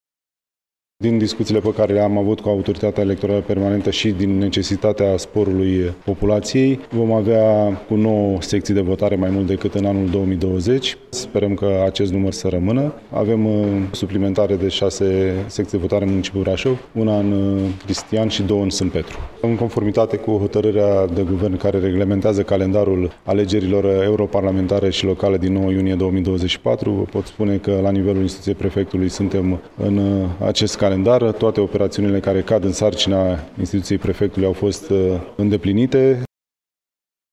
În județ vor fi 466 de secții de votare, cu nouă mai multe decât acum patru ani, spune prefectul de Brașov, Cătălin Văsii: